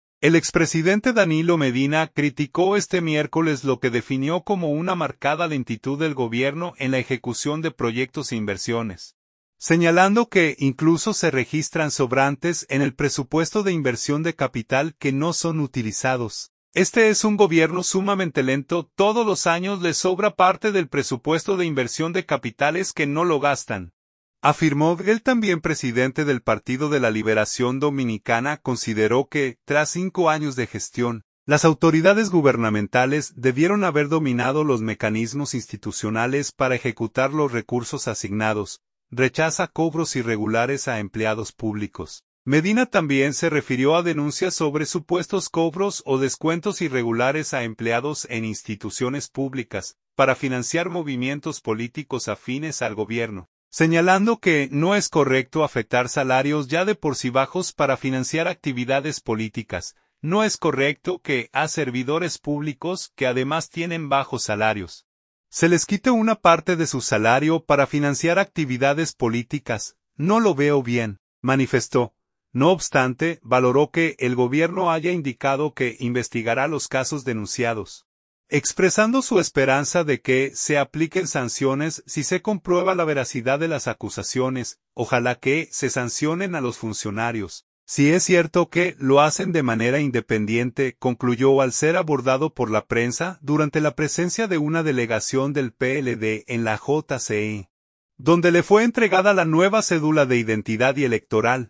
“Ojalá que se sancionen a los funcionarios, si es cierto que lo hacen de manera independiente”, concluyó al ser abordado por la prensa durante la presencia de una delegación del PLD en la JCE, donde le fue entregada la nueva cédula de identidad y electoral.